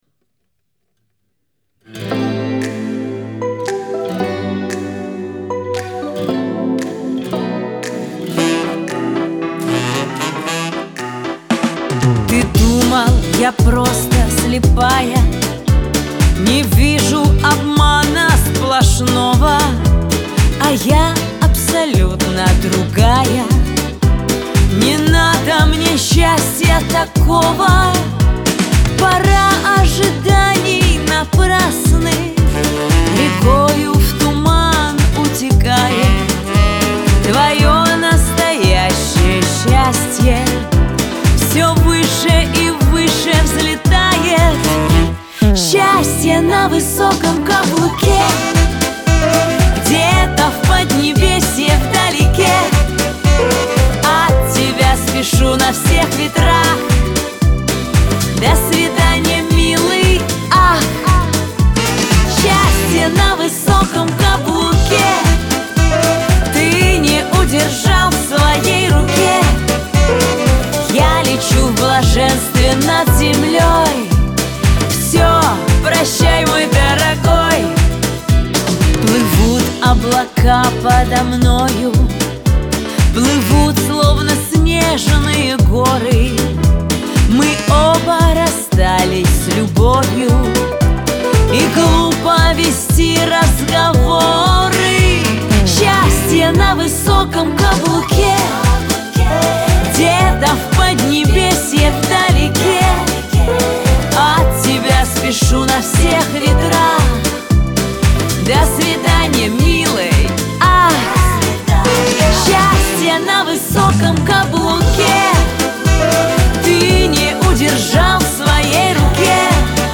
dance
Веселая музыка
pop